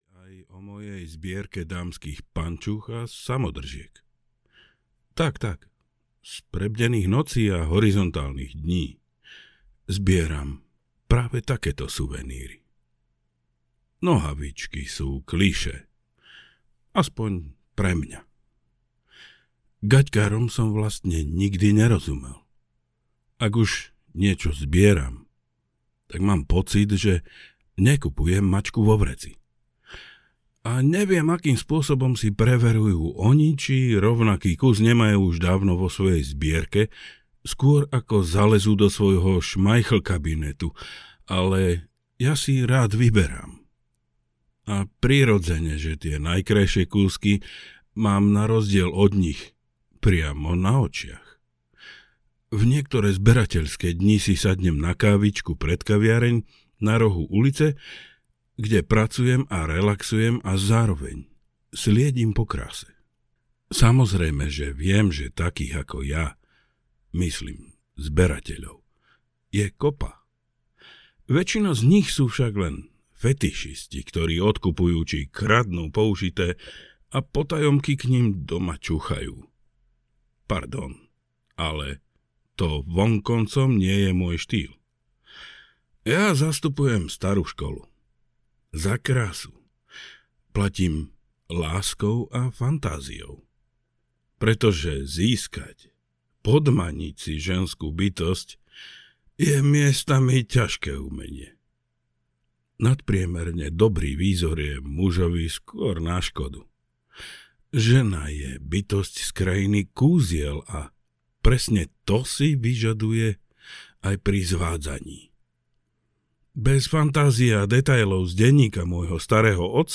13 iných poviedok audiokniha
Ukázka z knihy